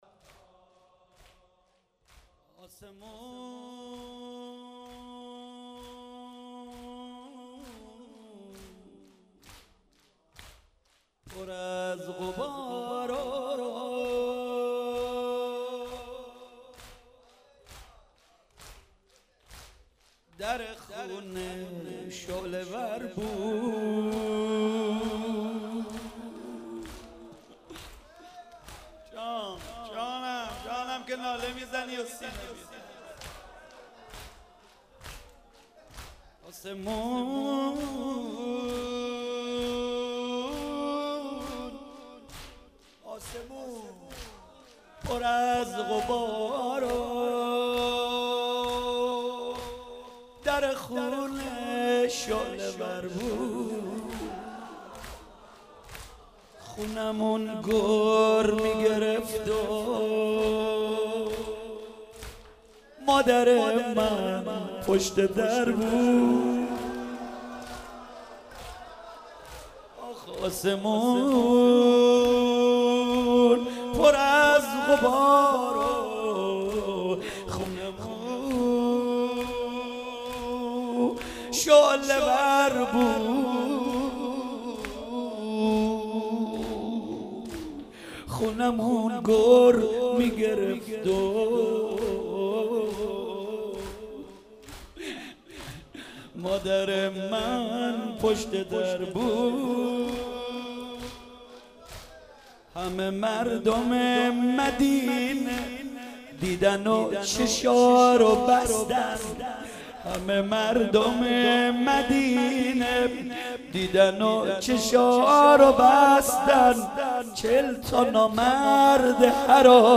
روضه
02 heiate eradatmandan shabe8.mp3